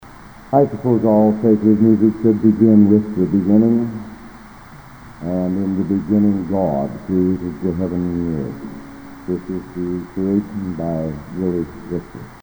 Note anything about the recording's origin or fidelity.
Collection: Broadway Methodist, 1980